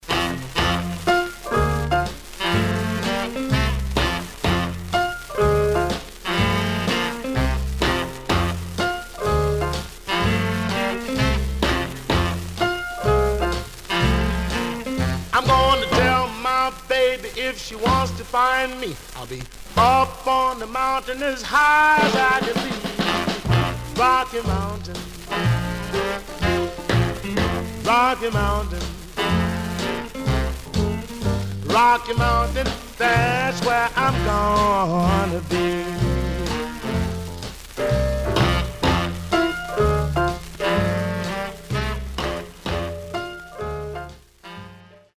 Some surface noise/wear Stereo/mono Mono
Rythm and Blues